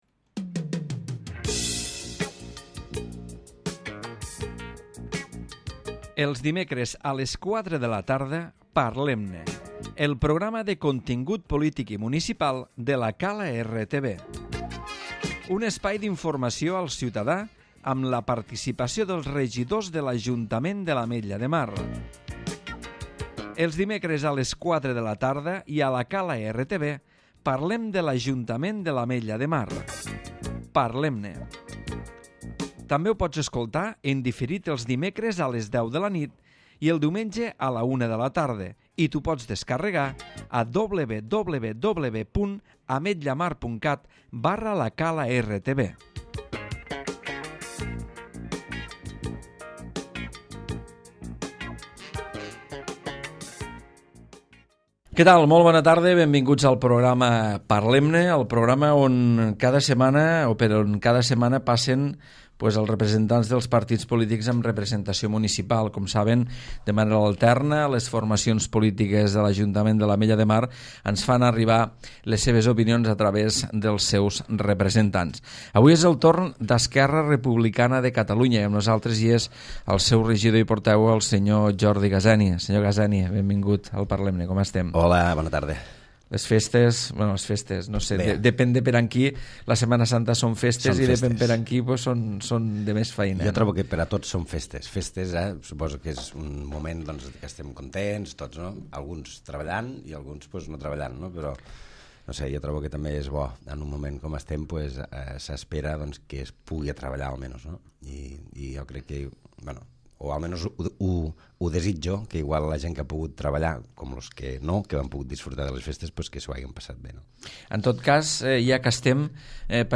Jordi Gaseni, regidor i portaveu del Grup Municipal d'ERC a l'Ajuntament de l'Ametlla de Mar parla dels temes d'actualitat municipal